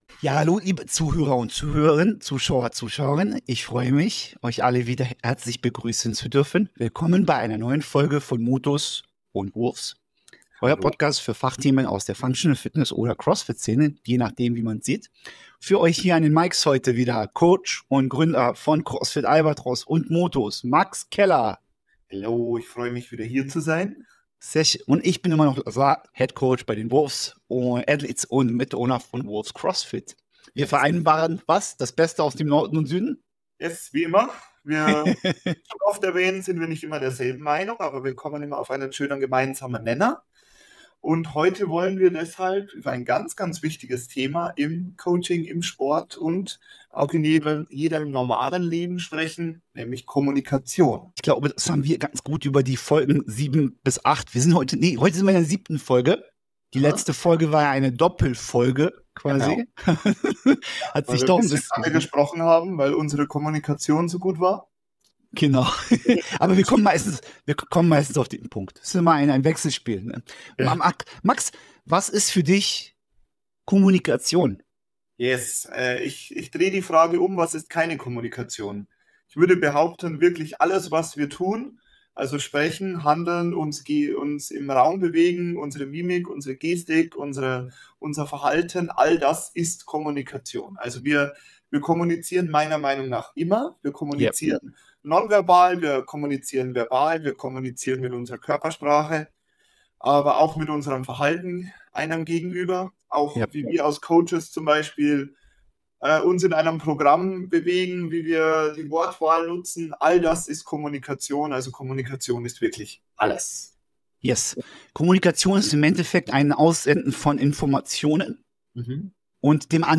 Die beiden Coaches betonen die Wichtigkeit der bewussten und achtsamen Kommunikation in Coaching und Therapie, um positive Ergebnisse zu erzielen. In diesem Teil des Gesprächs diskutieren die Teilnehmer die Herausforderungen der Kommunikation in verschiedenen Kontexten.